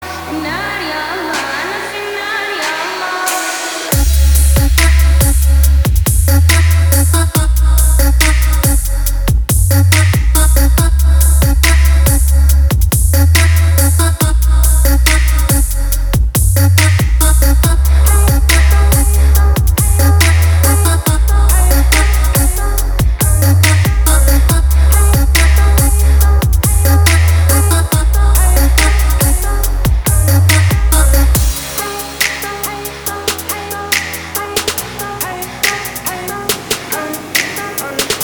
• Качество: 320, Stereo
громкие
мелодичные
Trap
басы
качающие
Bass
звонкие